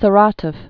(sə-rätəf)